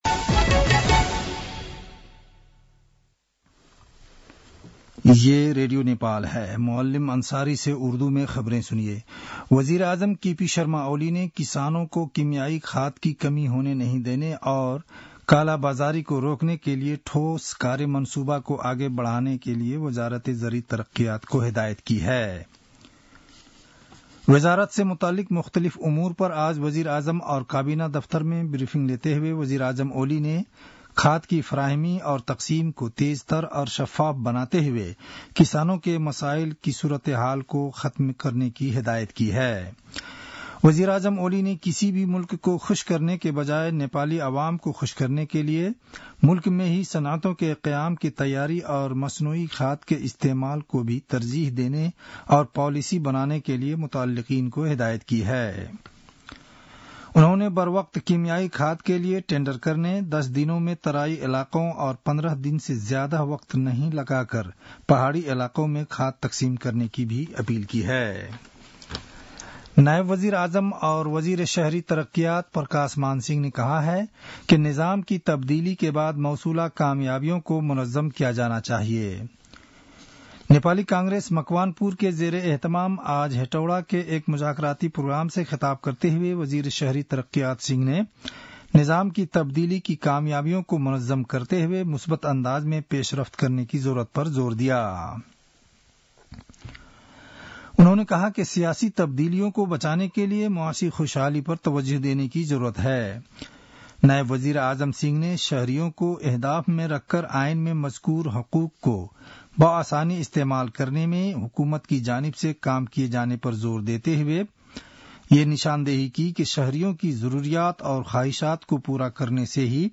उर्दु भाषामा समाचार : २ फागुन , २०८१
Urdu-news-11-01.mp3